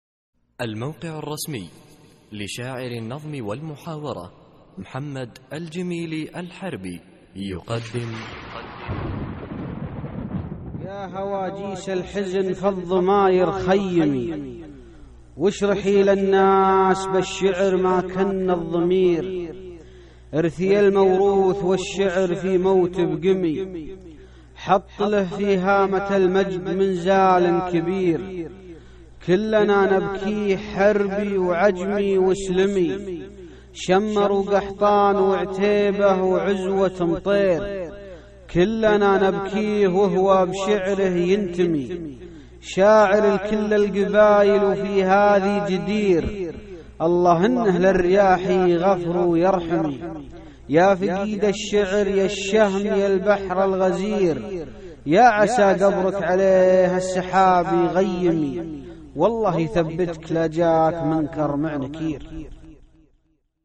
القصـائــد الصوتية
اسم القصيدة : موت بقمي ~ إلقاء